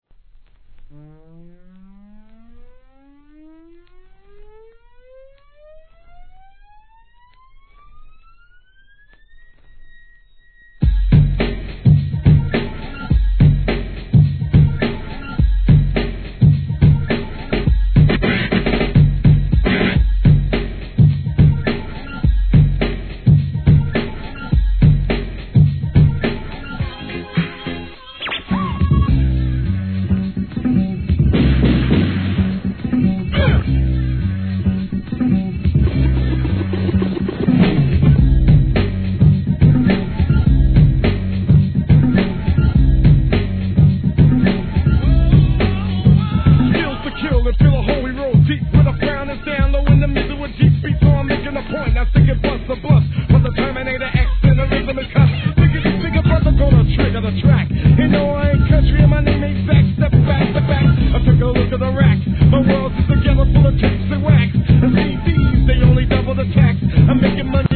HIP HOP/R&B
イントロの遊びがイカしたREMIXがGOOD!!